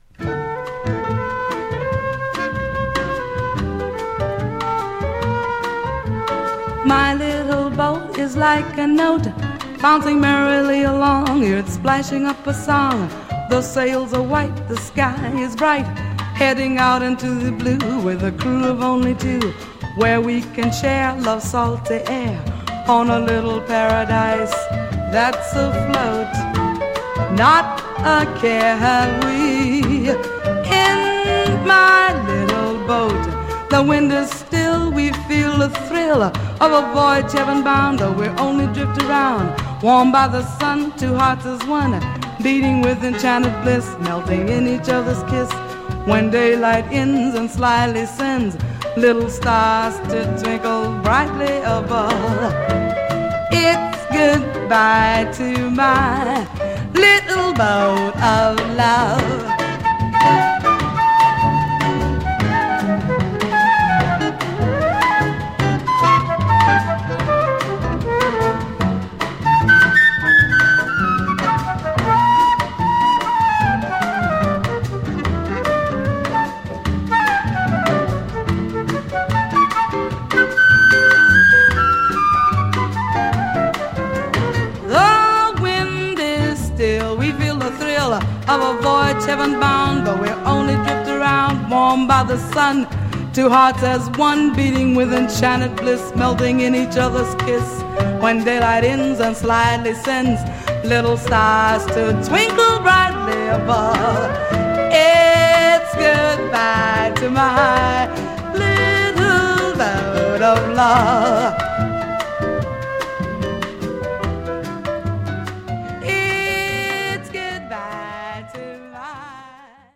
Jazz Vocal us
USの女性ジャズ・シンガー